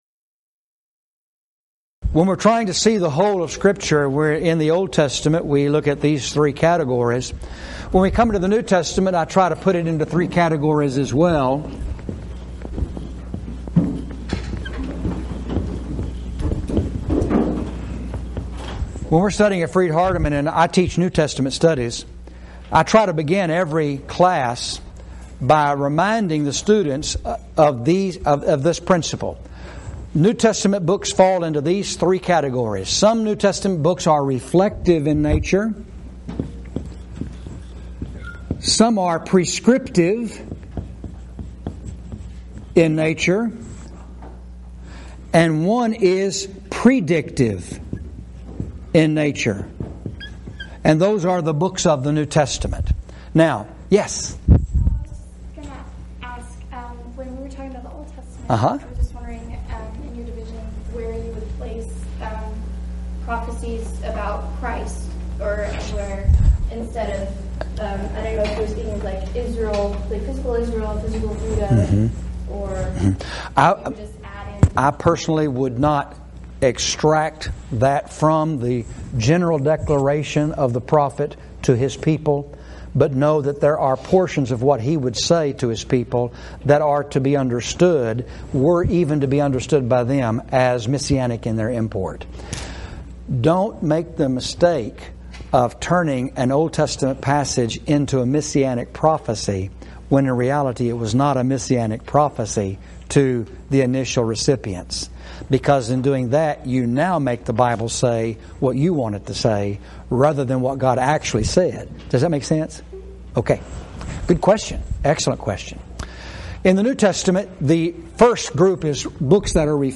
Event: Spring 2015 Special Studies Seminar
lecture